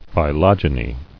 [phy·log·e·ny]